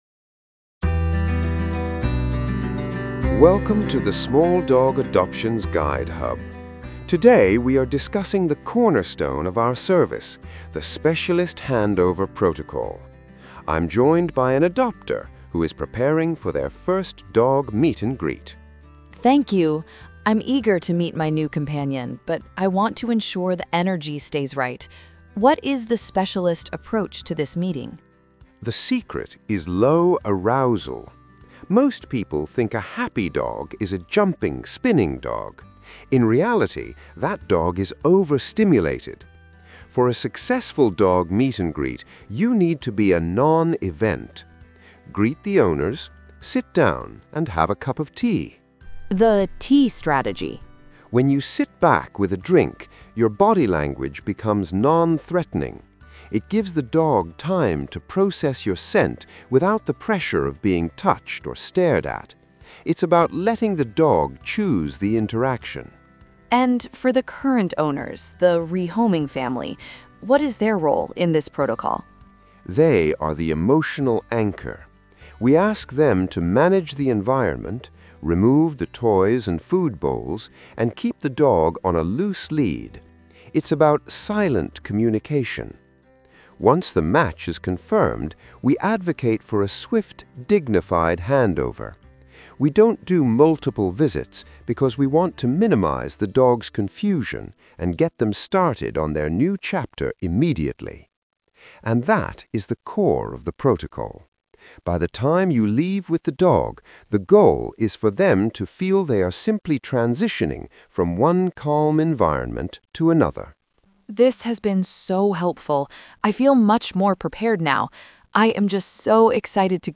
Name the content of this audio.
🎧 Audio Masterclass: